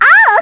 Epis-Vox_Damage_kr_02.wav